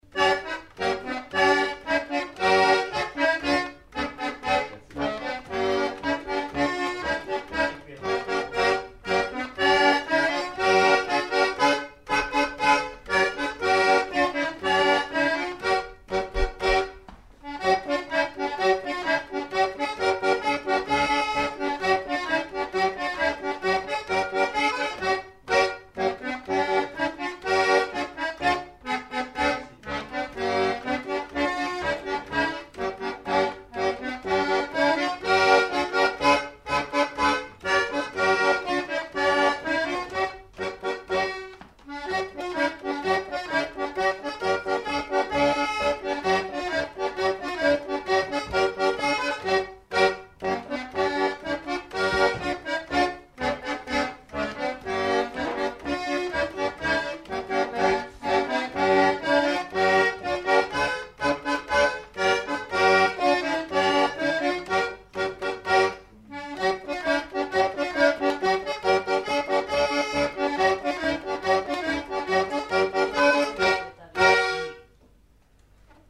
Lieu : Pyrénées-Atlantiques
Genre : morceau instrumental
Instrument de musique : accordéon diatonique
Danse : quadrille (3e f.)